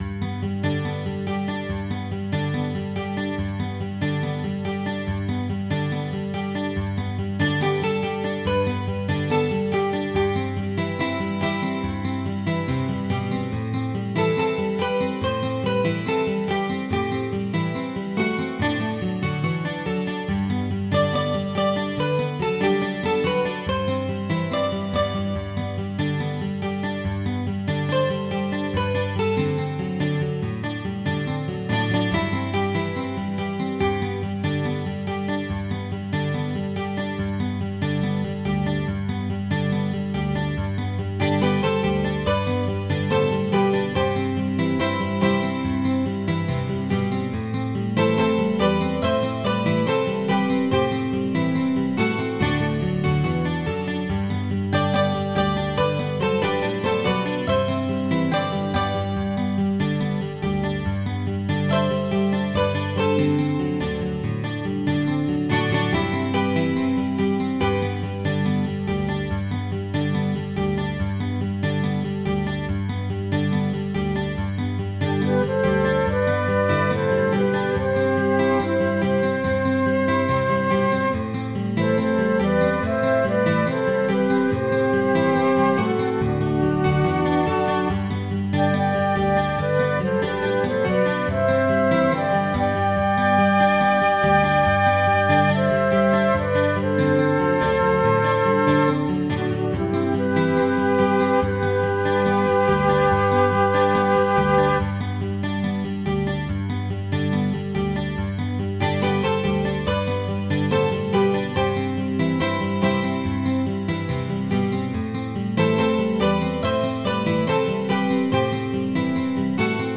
Midi File, Lyrics and Information to Lord Franklin